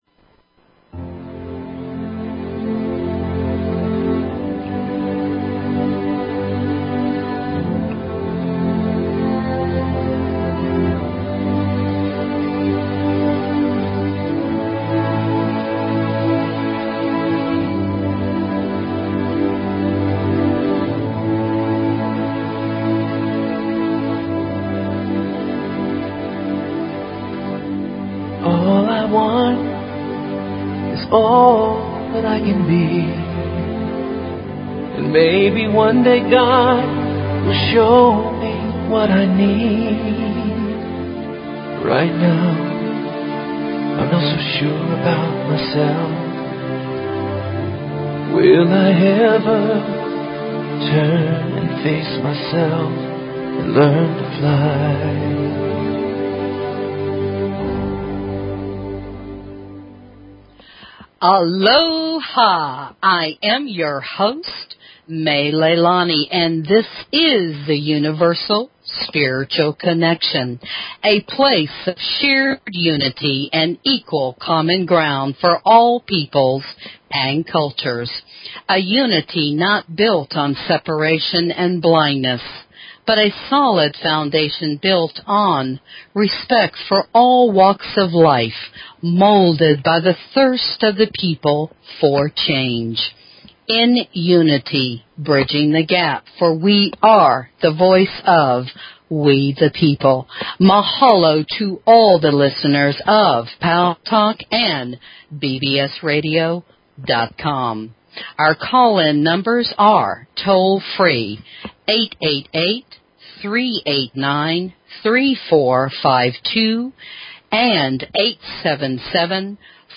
Talk Show Episode, Audio Podcast, Universal_Spiritual_Connection and Courtesy of BBS Radio on , show guests , about , categorized as
This show is Fun and opens up a whole new world of exciting topics and "Welcomes" your Questions and Comments. The show explores a variety of subjects from the Spiritual Eastern, Western and Native American Indian beliefs to the basic concepts of Love and Success.